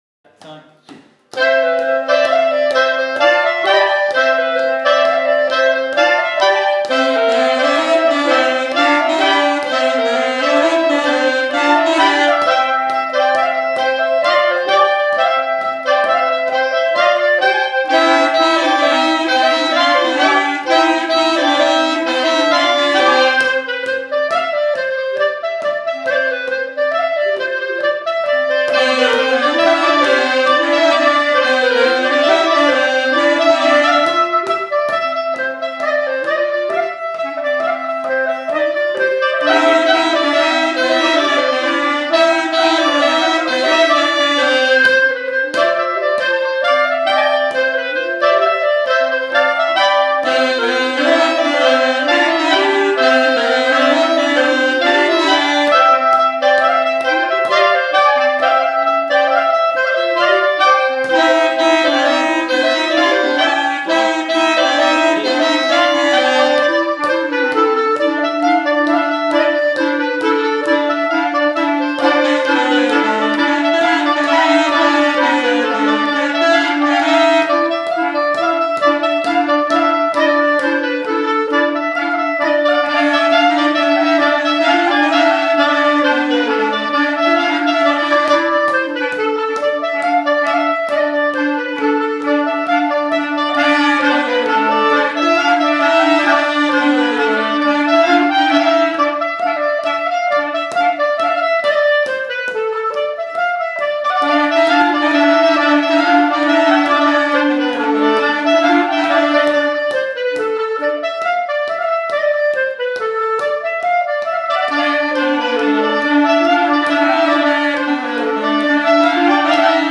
04_studio_clarinettes-ridees.mp3